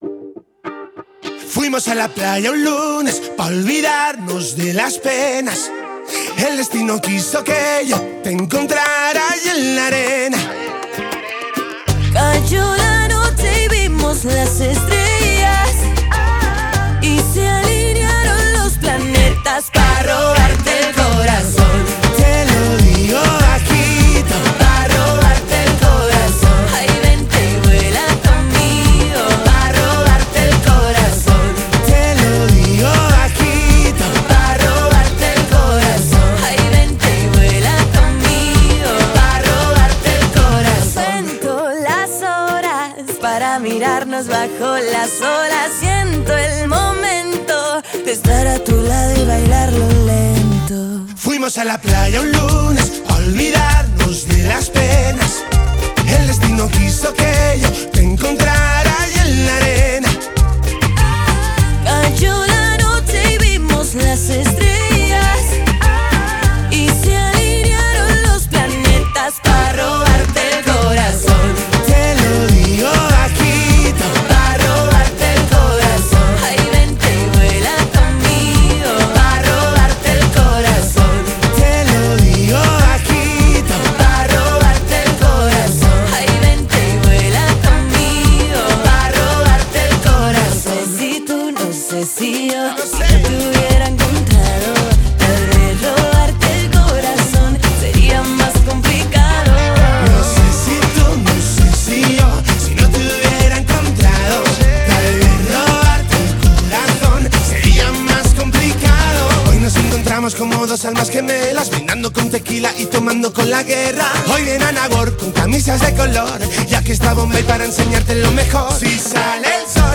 это зажигательная поп-музыка в стиле латин